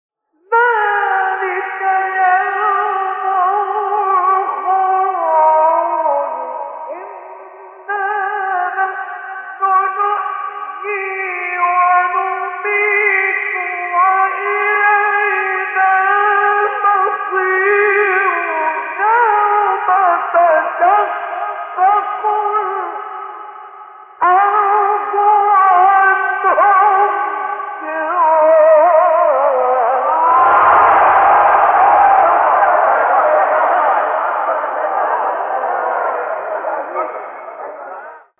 سوره: ق آیه: 42-44 استاد: مصطفی اسماعیل مقام: سه گاه قبلی بعدی